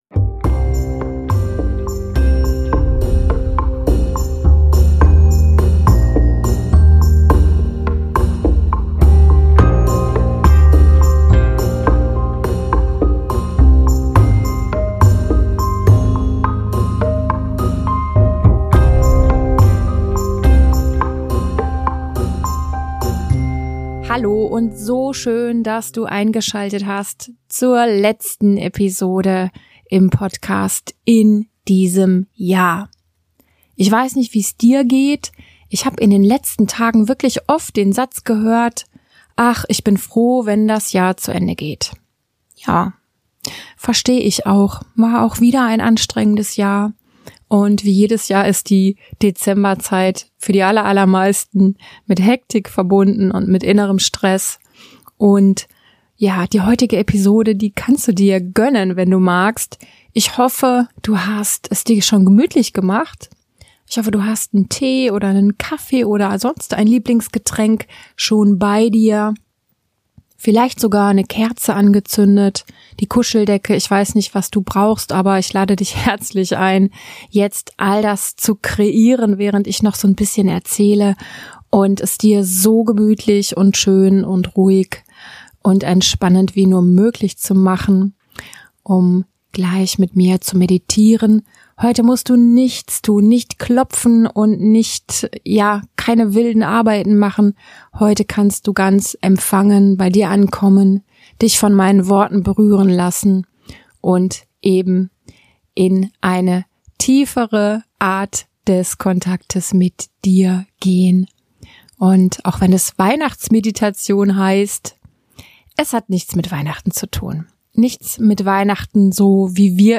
Weihnachtsmeditation ~ Tapping & Happiness Podcast
Es erwartet dich kein klassisch weihnachtliches Thema, was ich ganz gut finde für all jene, die diesen inneren Bezug gar nicht so haben. Aber es ist festlich und spirituell und passt in die Zeit: eine leichte Trance, eine wunderschöne Erfahrung, dein Licht zu entdecken, an einem anderen Ort, jenseits von Stress und Sorgen.